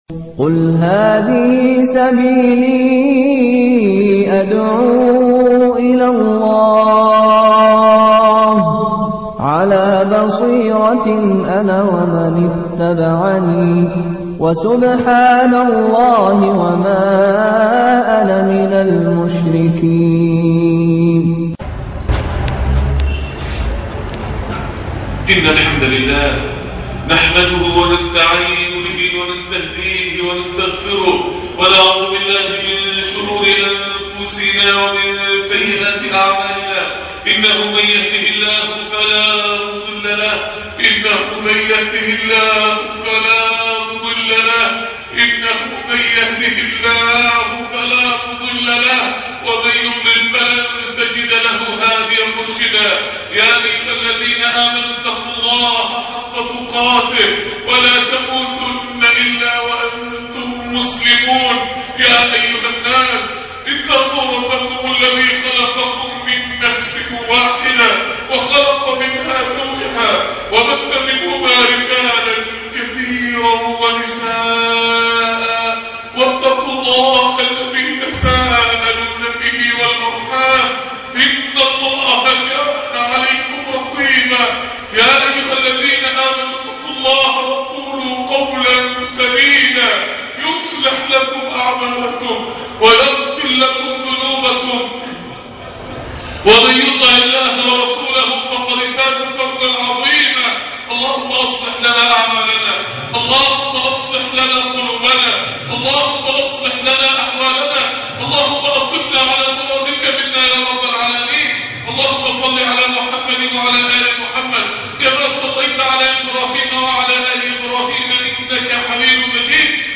خطبة جمعة